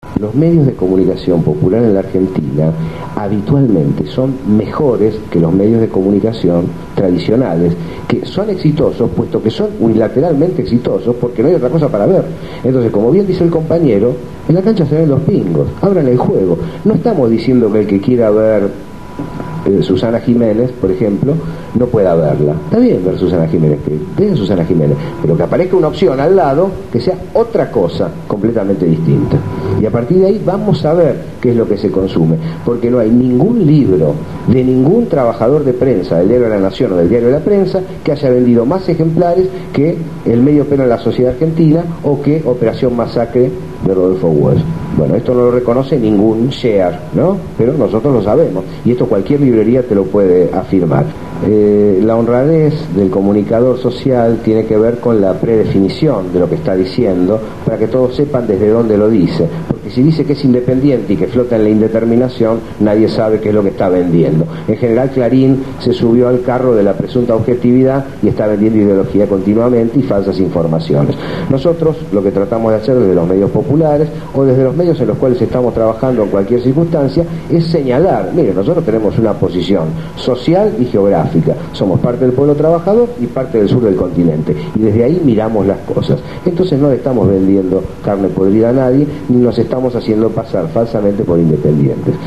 Con un salón colmado discutimos acerca de una nueva ley de radiodifusión y cual sería el papel que deben cumplir las organizaciones sociales para que la democratización de la comunicación sea una realidad.